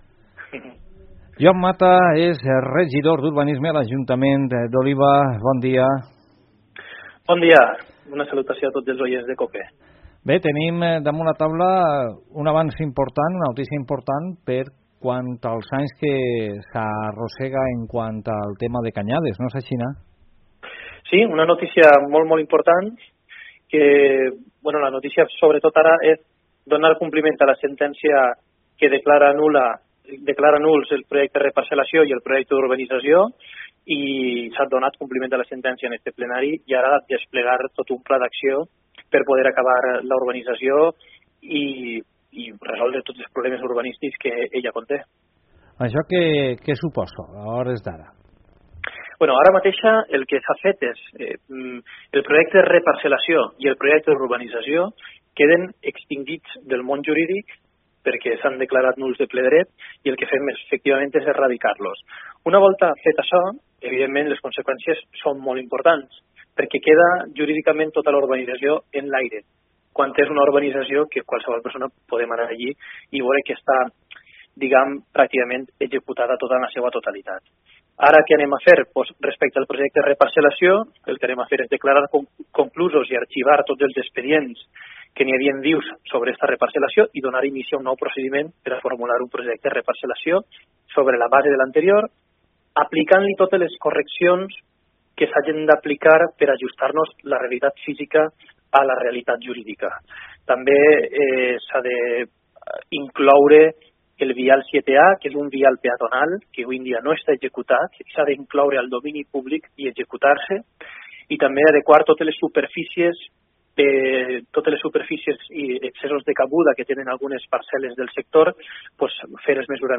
concejal Mata Cots (audio)